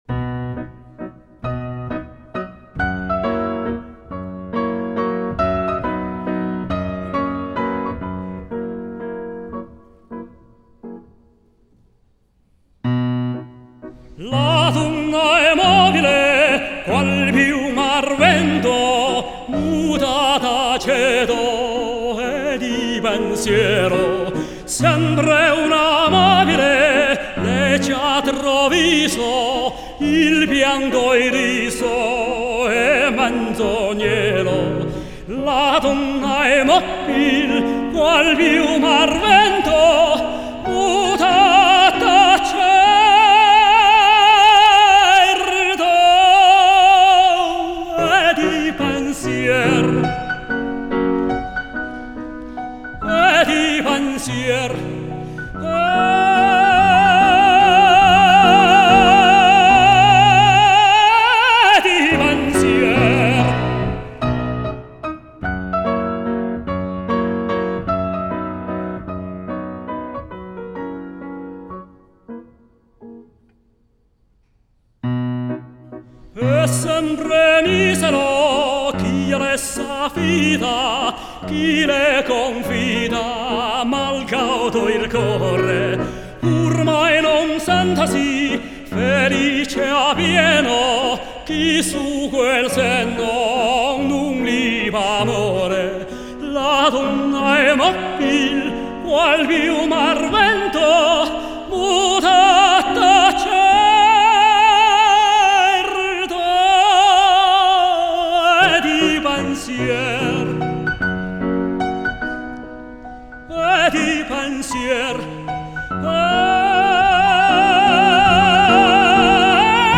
2006年11月5日宁波音乐会实况录音